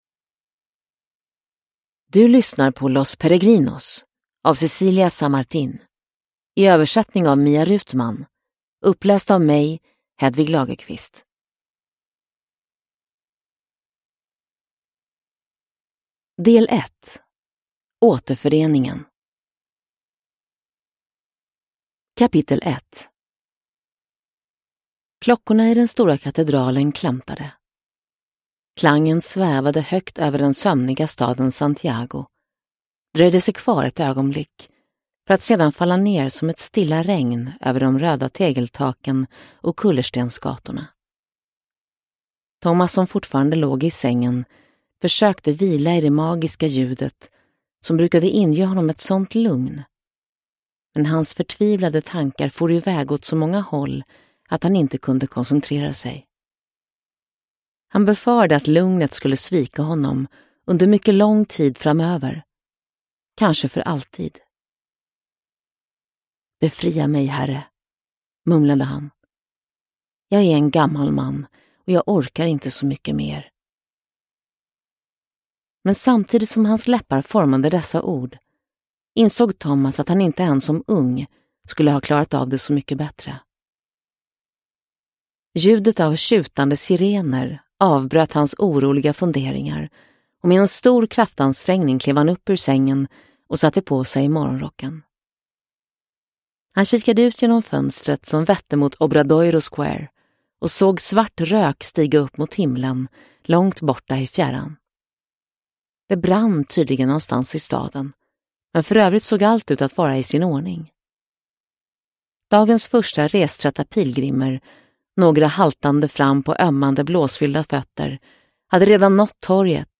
Los Peregrinos – Ljudbok – Laddas ner